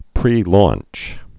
(prēlônch, -lŏnch)